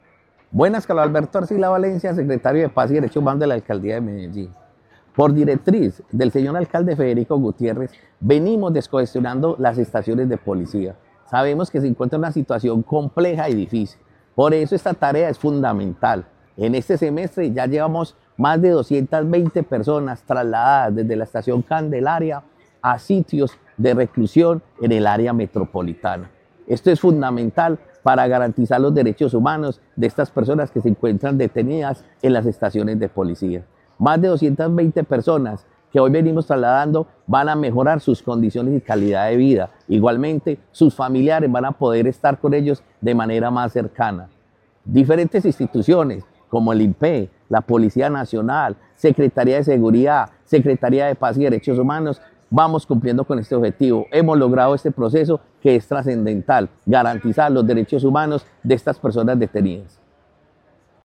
Declaraciones secretario de Paz y Derechos Humanos, Carlos Arcila Valencia La Alcaldía de Medellín, a través de la Secretaría de Paz y Derechos Humanos, ha intensificado su labor para garantizar condiciones adecuadas para la población carcelaria.
Declaraciones-secretario-de-Paz-y-Derechos-Humanos-Carlos-Arcila-Valencia.mp3